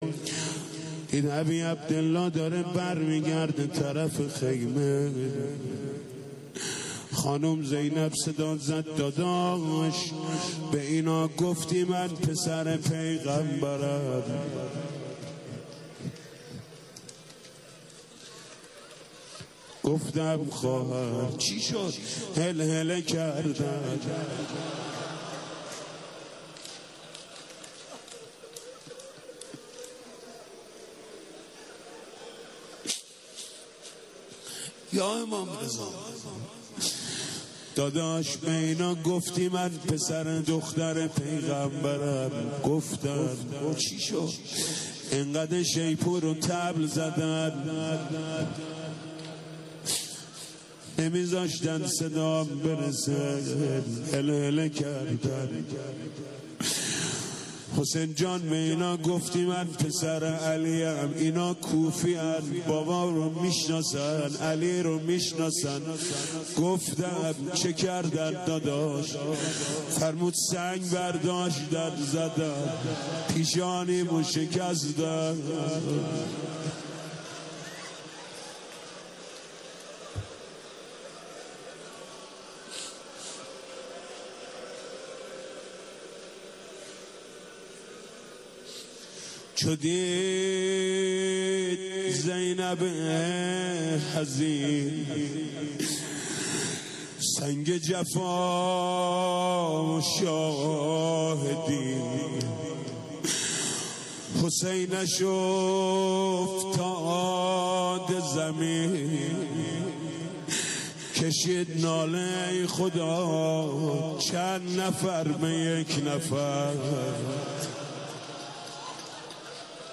حاج محمود کریمی/مراسم ماه مبارک رمضان96
بخش پنجم/روضه